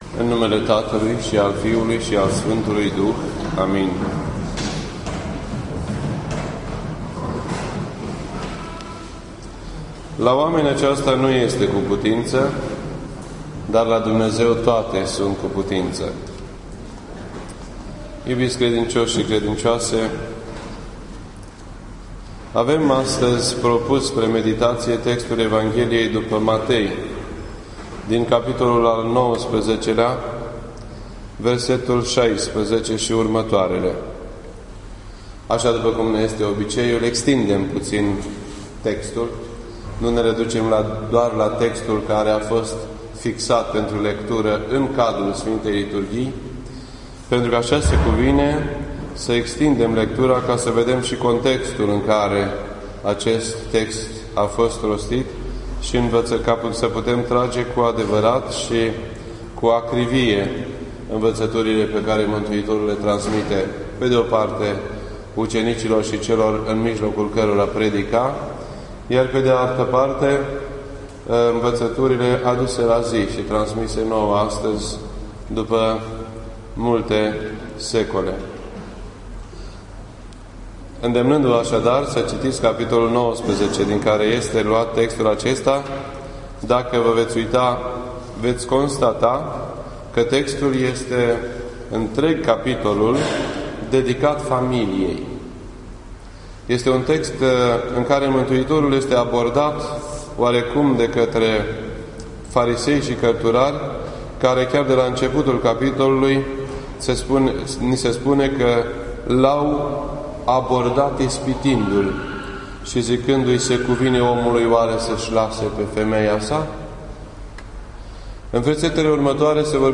This entry was posted on Sunday, August 26th, 2012 at 8:08 PM and is filed under Predici ortodoxe in format audio.